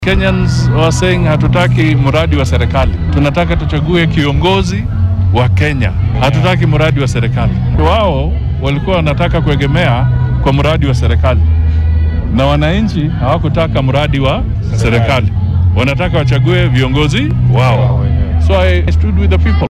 Arrintan oo uu ka hadlay xilli uu shalay ku sugnaa deegaanka Rongai ee ismaamulka Kajiado ayaa ku soo aadaysa ka dib markii ra’iisul wasaare ku xigeenkii hore ee dalka iyo madaxa xisbiga Ford-Kenya Moses Wetangula lagu dhaliilay inay la safteen Ruto.